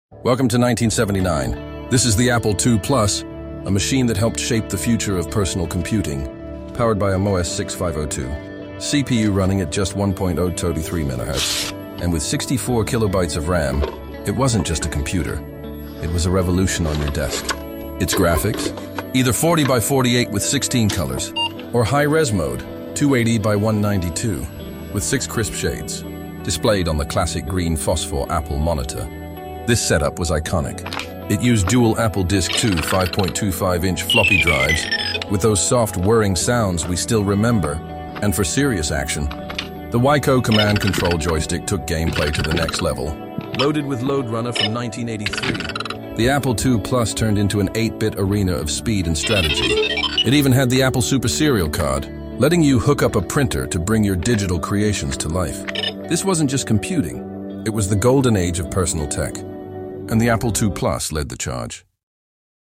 Relive the magic of 1979 with the Apple II Plus — the machine that turned homes into digital playgrounds. With Lode Runner blazing across a green phosphor screen and dual floppy drives humming, this was true 8-bit glory.